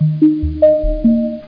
TransferComplete.mp3